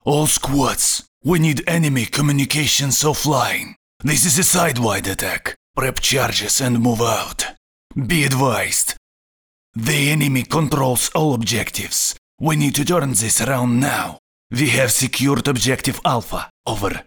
Russian Accent